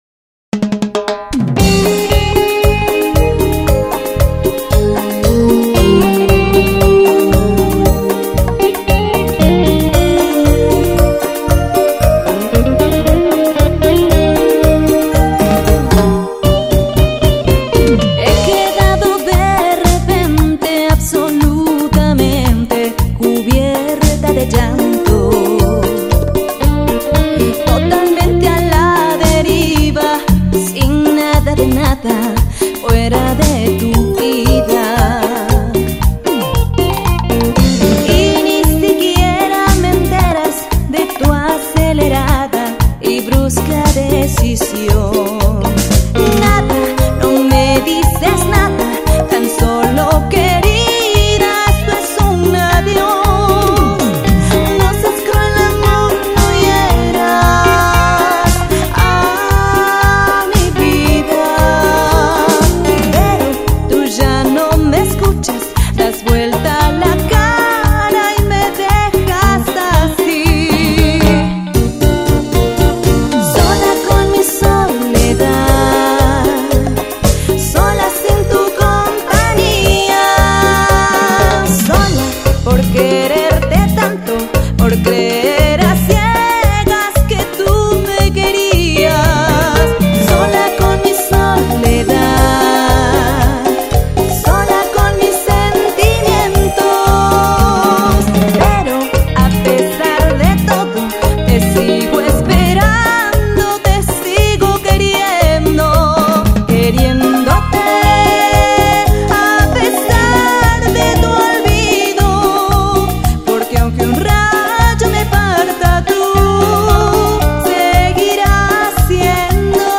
Llegó el momento de hacer CUMBIA !!